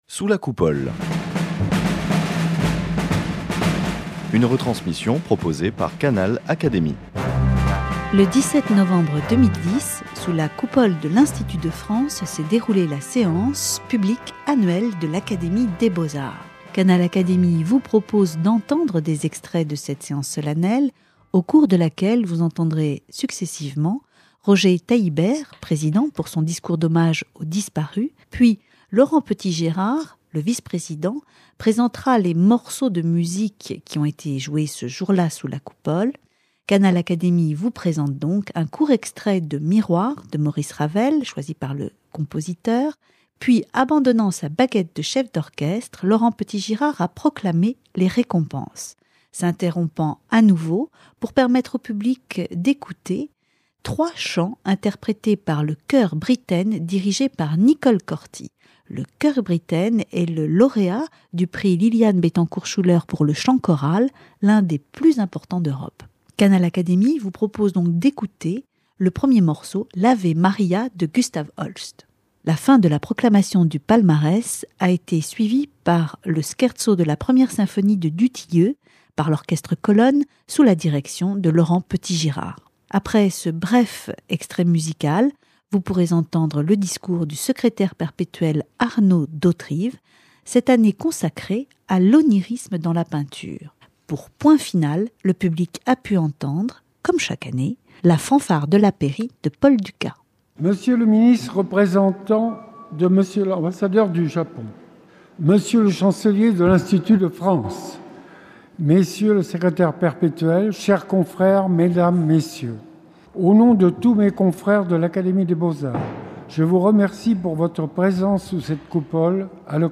Palmarès et discours sont accompagnés pour la circonstance, d’un orchestre symphonique et d’un chœur pour le plus grand plaisir des académiciens, des lauréats et des invités. Canal Académie vous propose d’écouter des extraits de cette séance solennelle qui s’est déroulée le 17 novembre 2010.
L'architecte Roger Taillibert a salué la mémoire de ses confrères disparus au cours de l’année écoulée.